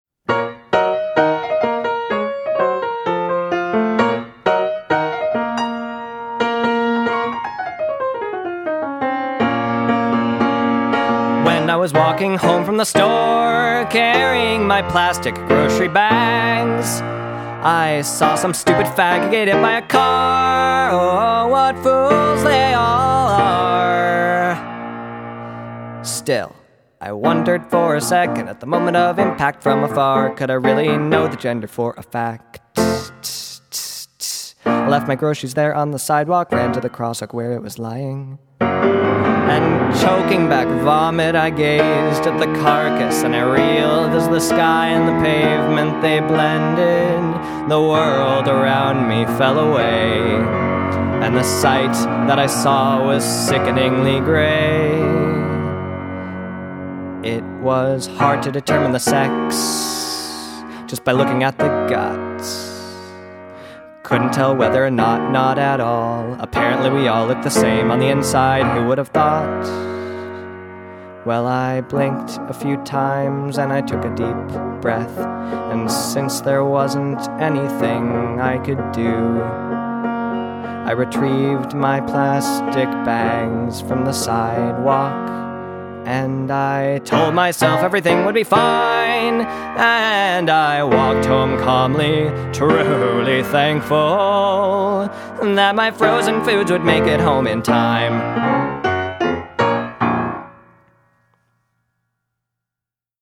Description of Submission:  "An original satrical song, written from the perspective of a queerphobic person who is shaken not at the loss of human life, but at the "grotesque" notion that gender and sexuality are fluid and we are all essentially the same."
The song was catchy and also relevant in today's political climate.